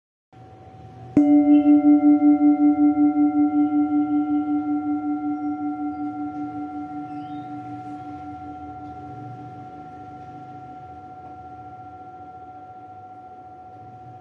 Buddhist Hand Beaten Naga Singing Bowl with Stand, Old, Select Accessories
Material Bronze
Vocals range up to 3 octaves, and 4 octaves are rare to find.